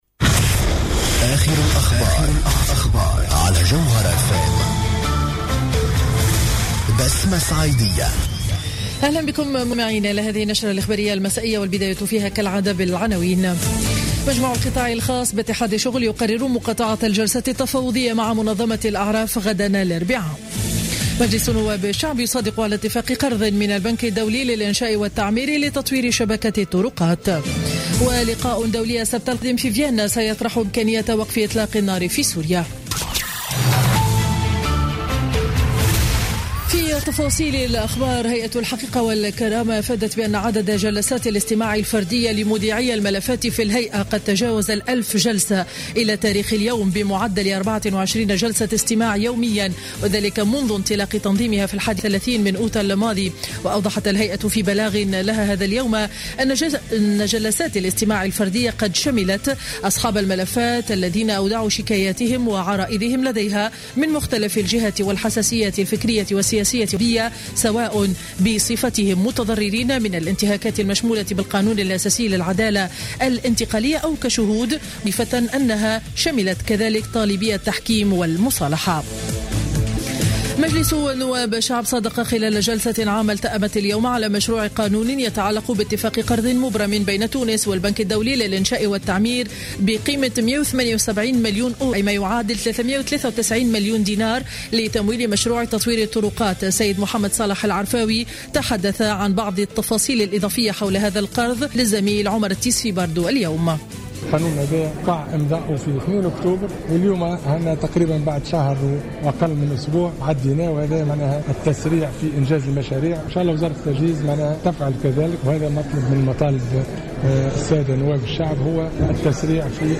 نشرة أخبار السابعة مساء ليوم الثلاثاء 10 نوفمير 2015